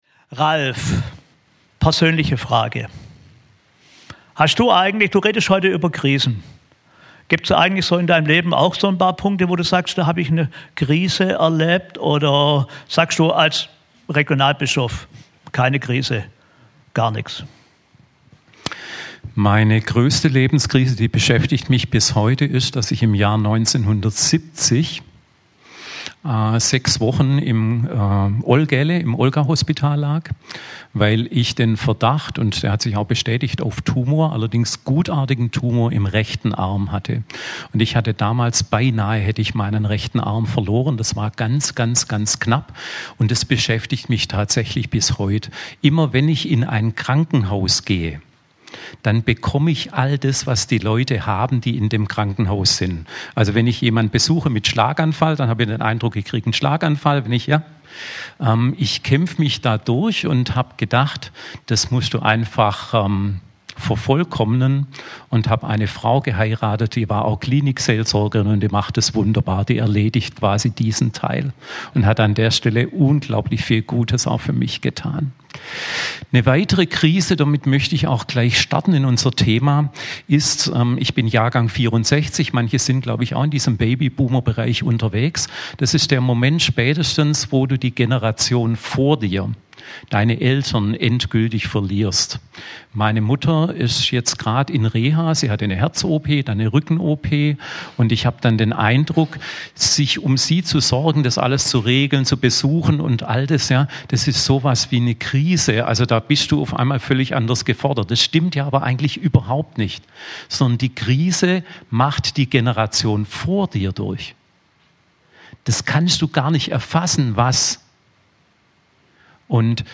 Podcast vom letzten Gottesdienst. Crossline - ein Gottesdienst mit interessanten Themen und Referenten, viel Musik (Singteam und Band).